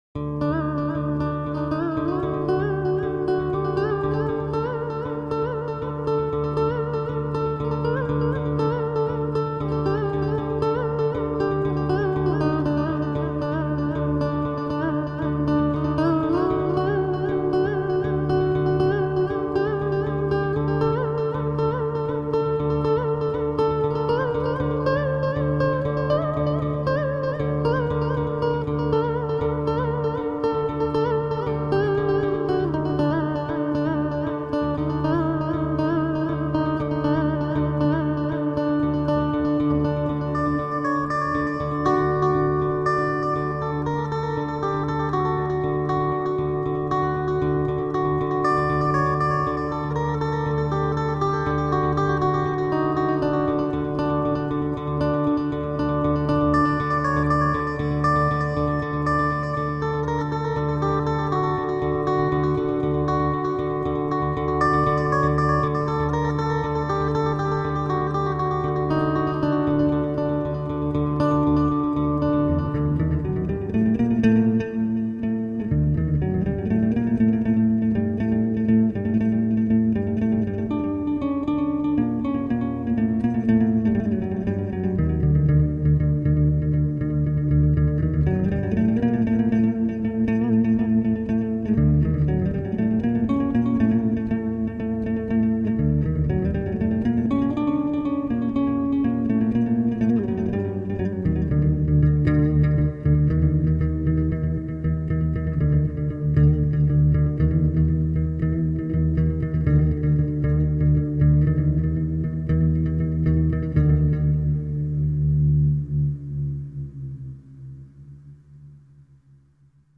Acoustic new age and jazz guitar..